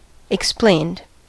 Ääntäminen
Ääntäminen US Haettu sana löytyi näillä lähdekielillä: englanti Käännös 1. interpretatus 2. dissertus 3. expansus 4. explicātus {m} 5. expandendus 6. explānātus Explained on sanan explain partisiipin perfekti.